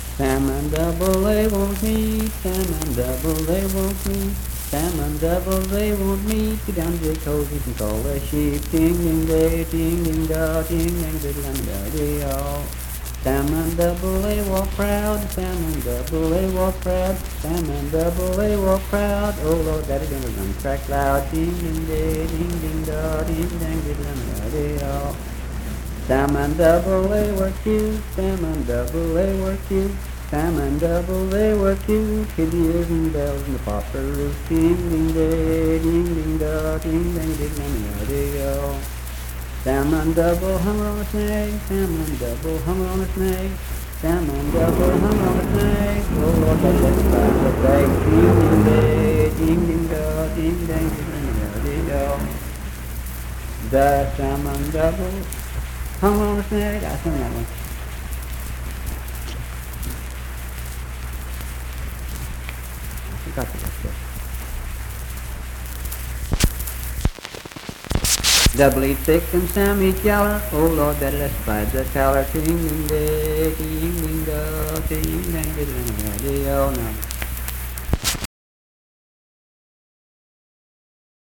Unaccompanied vocal music performance
Verse-refrain 5(6w/R).
Voice (sung)